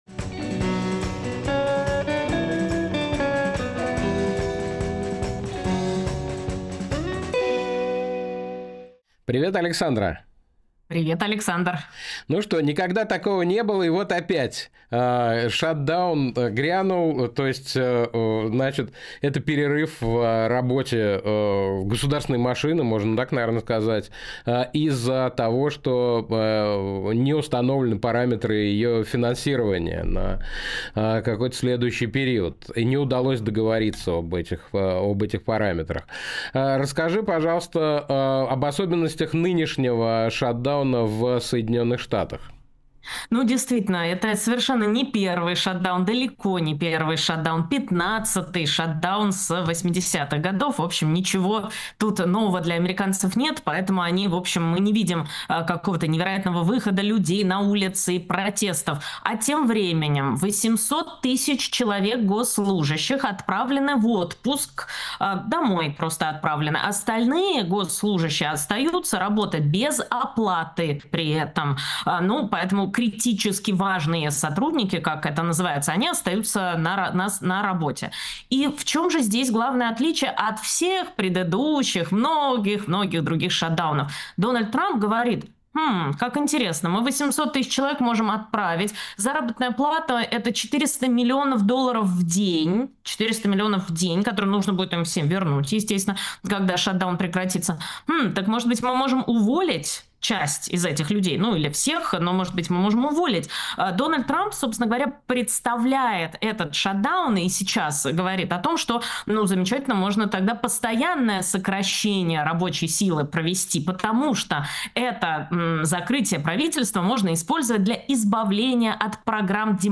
Фрагмент эфира от 2 октября